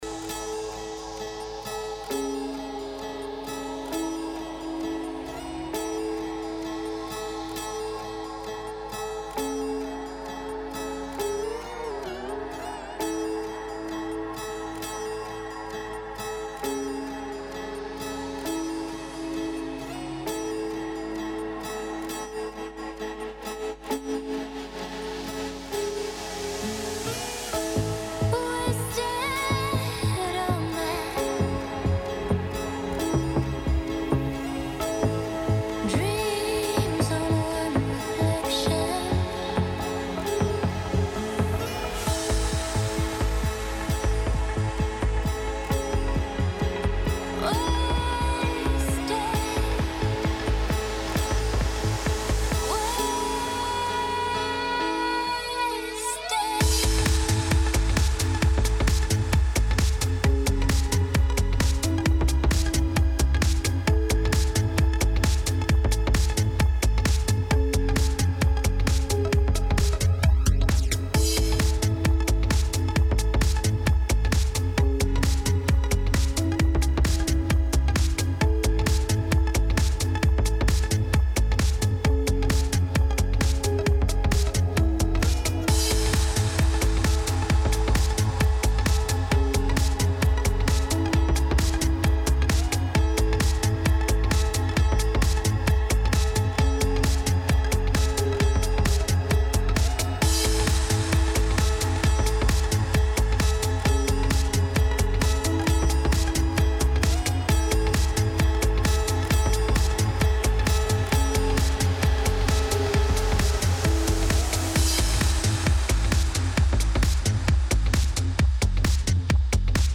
Live Mixes